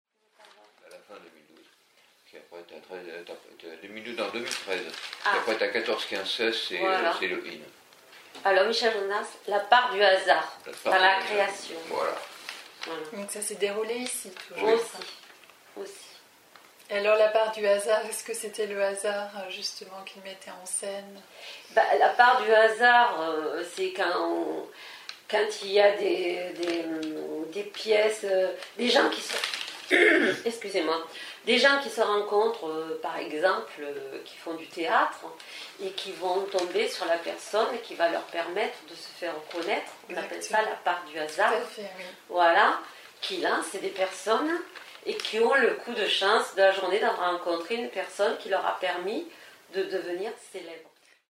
« La part du hasard dans la création artistique, » c’est à la base celle évoquée par Michel Jonasz, il la décrit selon ses propres mots en 2013 lors d’un débat animé par le pôle Arts et Sciences (CNRS) durant le festival OFF. Selon lui il s’agit là d’un phénomène soudain, mystérieux et totalement hétéroclite.